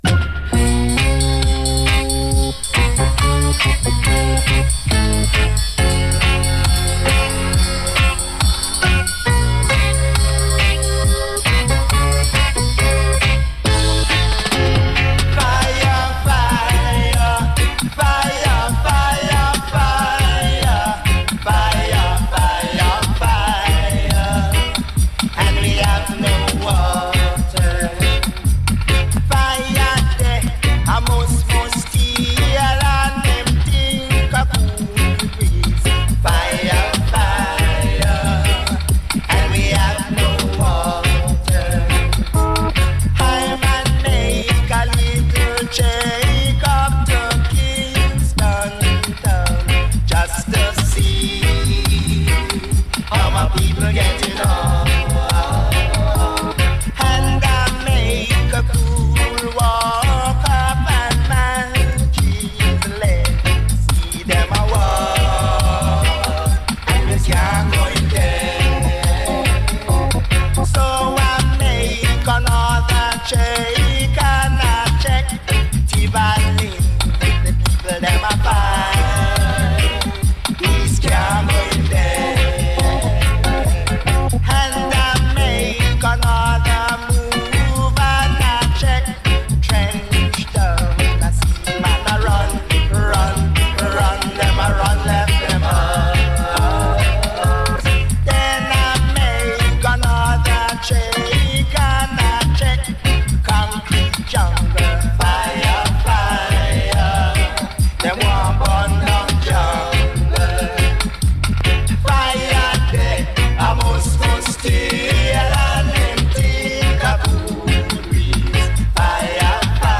My internet signal went down = 2nd hour missing !!